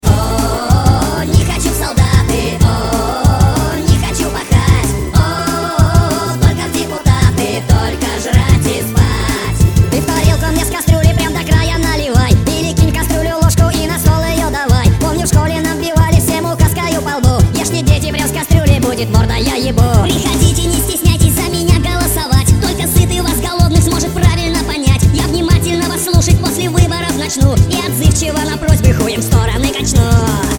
• Качество: 192, Stereo
забавные
смешной голос
цикличные